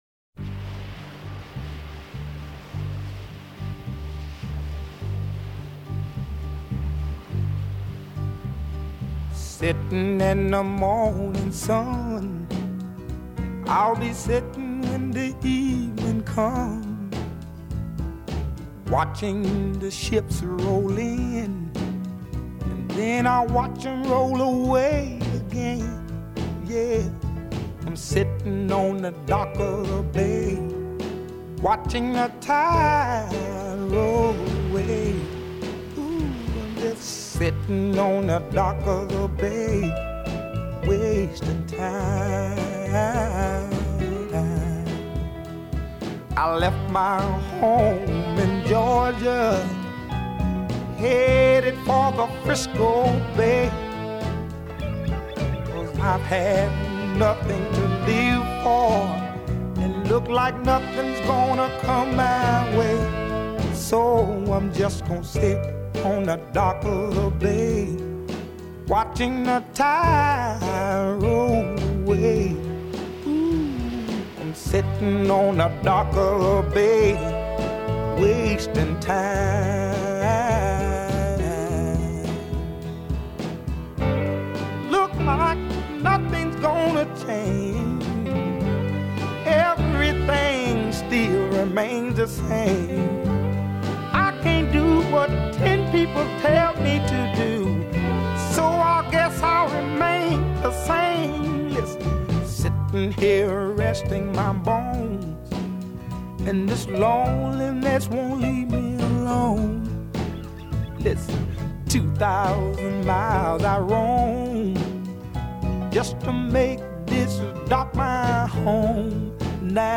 TEMPO : 103
Ce morceau n'a que des accords Majeurs !!
Version Originale